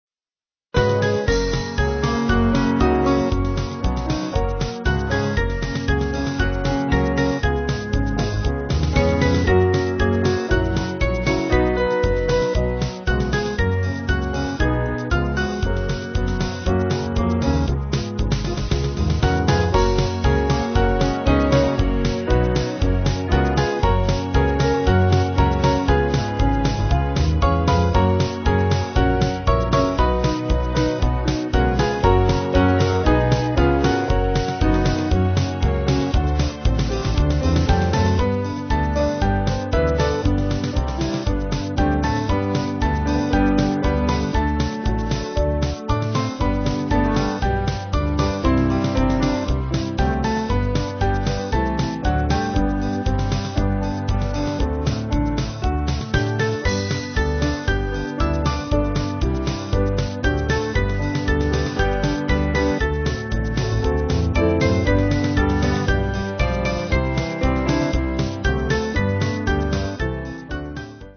Small Band
(CM)   5/Gb-G-Ab